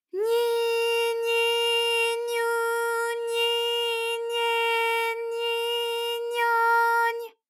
ALYS-DB-001-JPN - First Japanese UTAU vocal library of ALYS.
nyi_nyi_nyu_nyi_nye_nyi_nyo_ny.wav